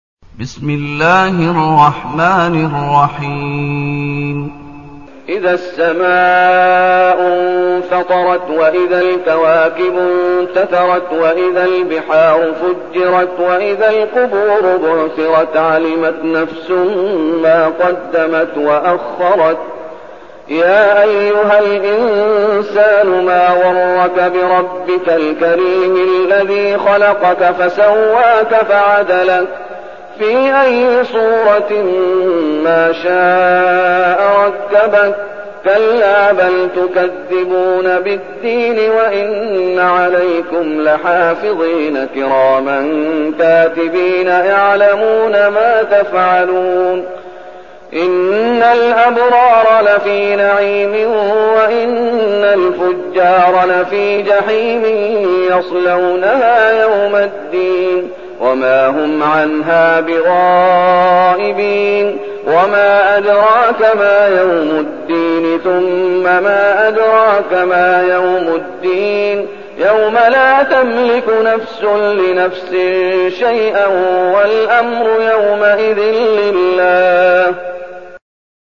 المكان: المسجد النبوي الشيخ: فضيلة الشيخ محمد أيوب فضيلة الشيخ محمد أيوب الانفطار